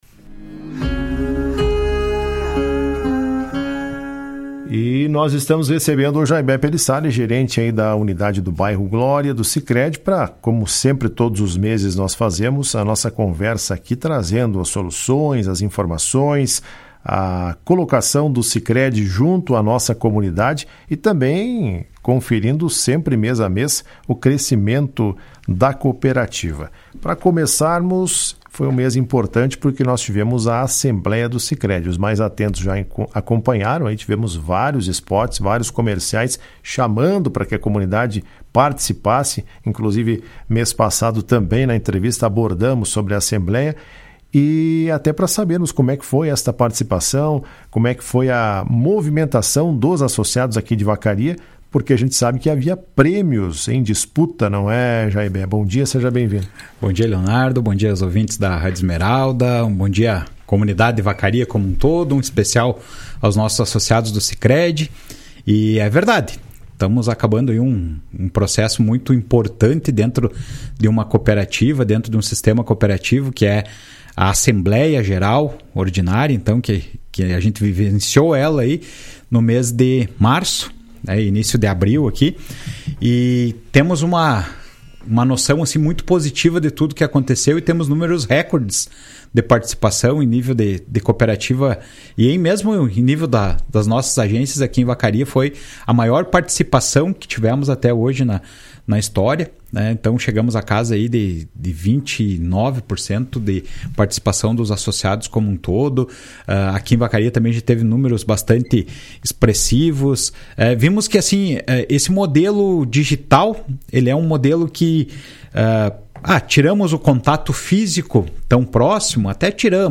Em entrevista ao programa Comando Geral da Rádio Esmeralda nesta segunda-feira (13)